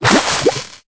Cri_0848_EB.ogg